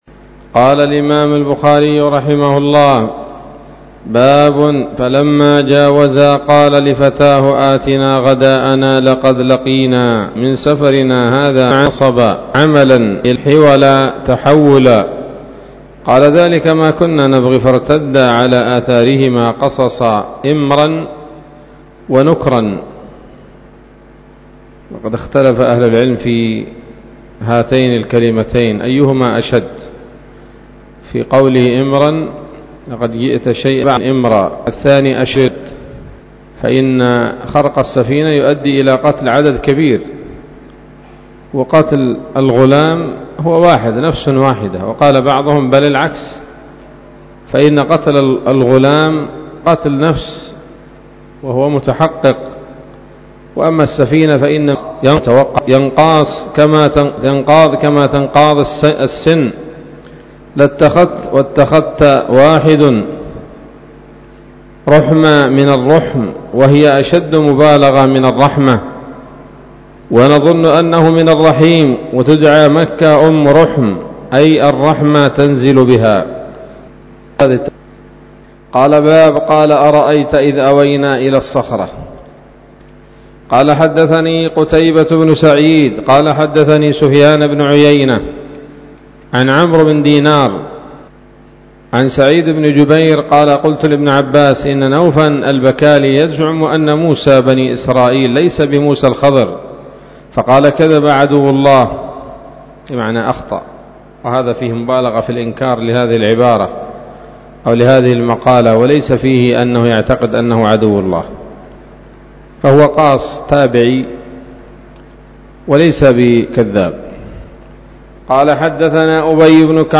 الدرس الخامس والستون بعد المائة من كتاب التفسير من صحيح الإمام البخاري